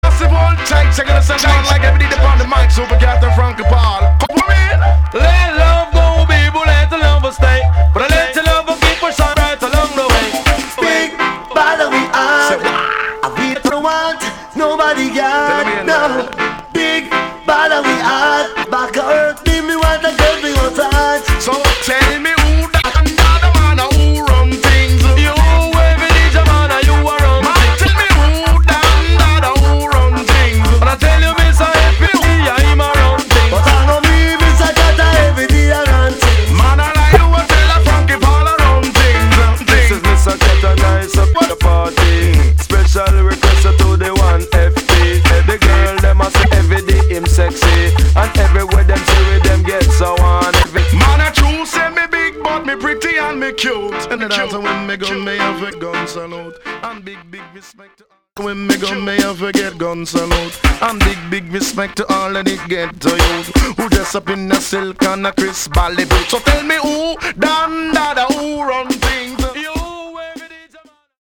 TOP >80'S 90'S DANCEHALL
B.SIDE Remix
EX-~VG+ 少し軽いチリノイズが入りますが良好です。